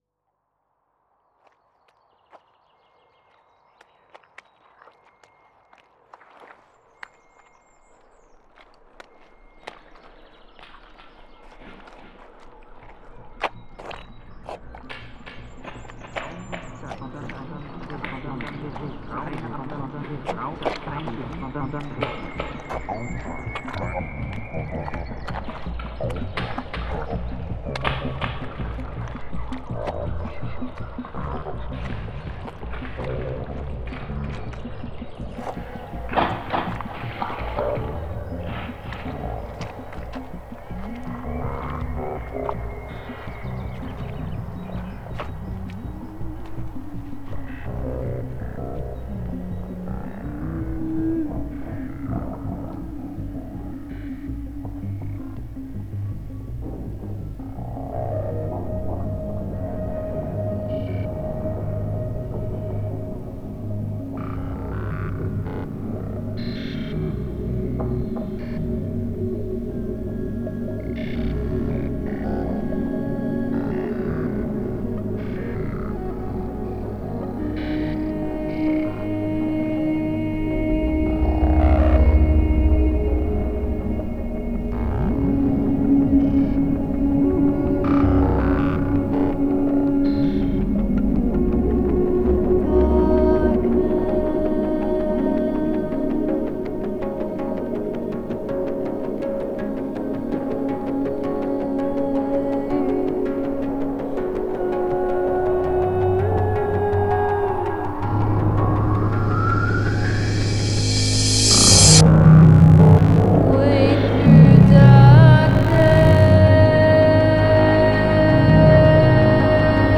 invites you to step into a shifting, sonic swamp